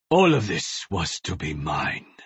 Hanzo Voiceline 3 Overwatch